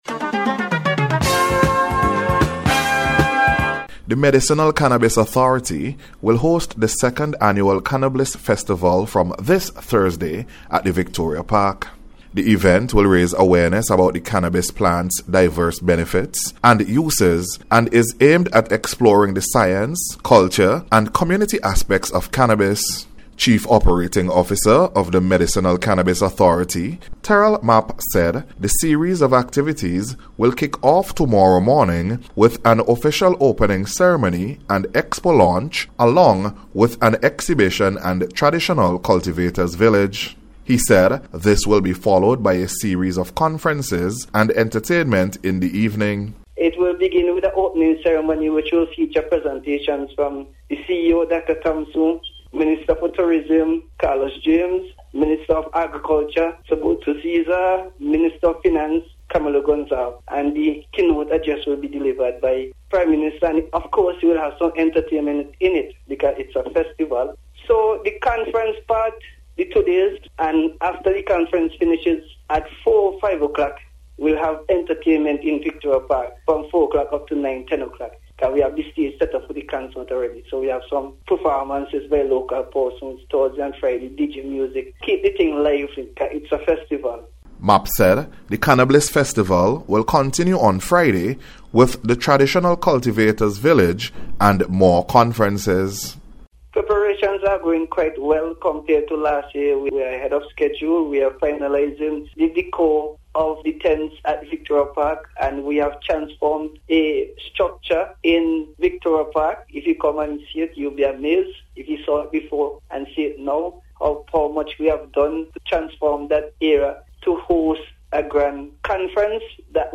SVG-CANNABLISS-FESTIVAL-REPORT.mp3